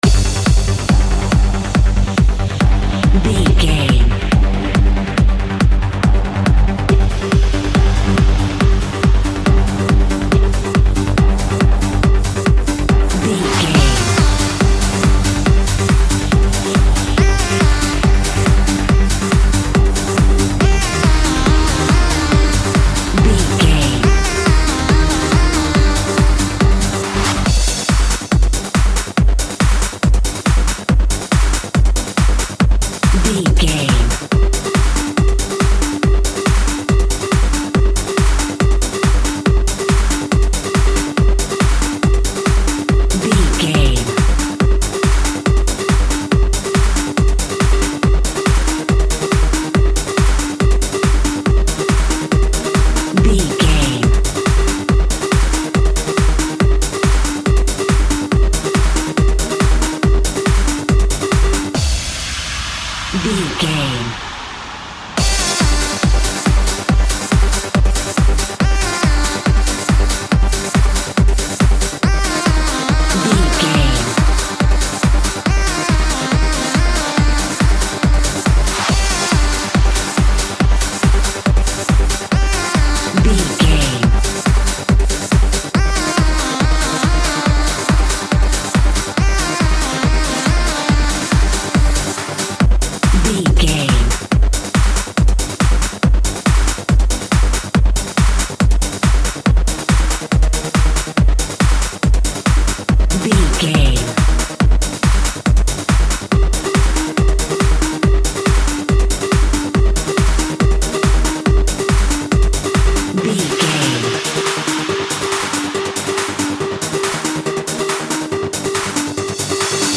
Aeolian/Minor
Fast
aggressive
powerful
dark
driving
energetic
intense
drums
synthesiser
drum machine
acid house
electronic
uptempo
synth leads
synth bass